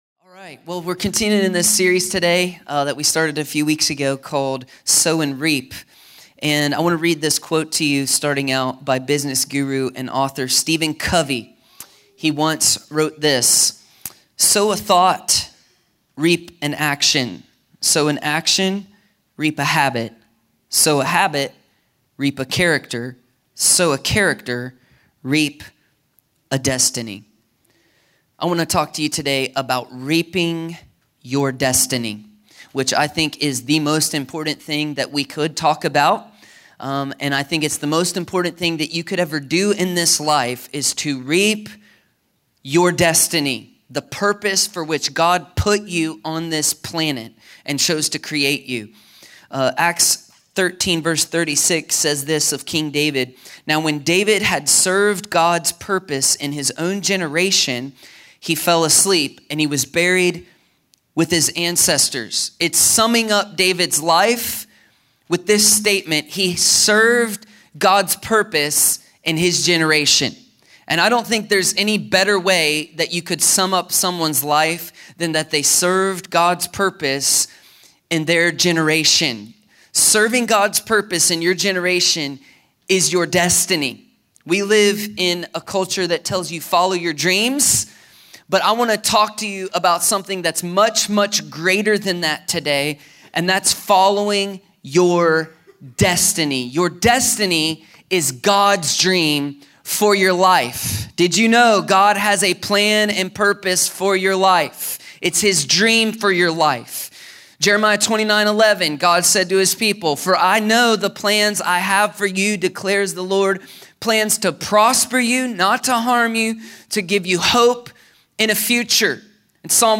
A sermon from the series “Sow & Reap.”…